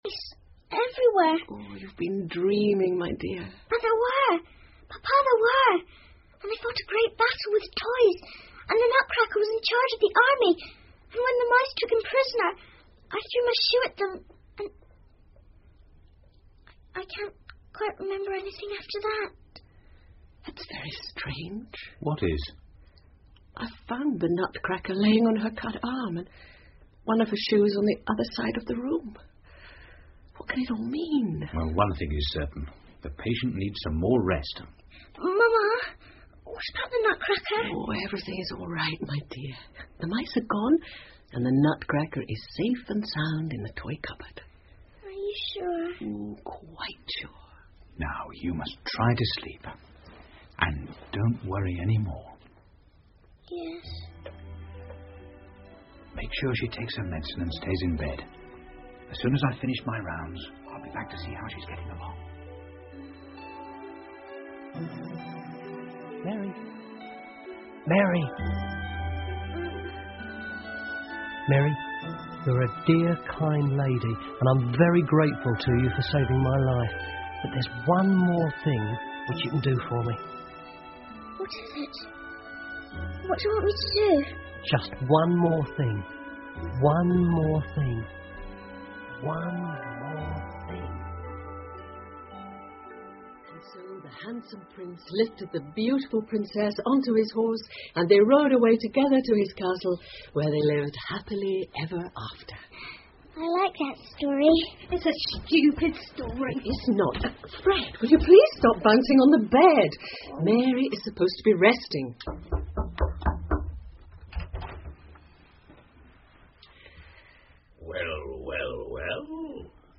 胡桃夹子和老鼠国王 The Nutcracker and the Mouse King 儿童广播剧 11 听力文件下载—在线英语听力室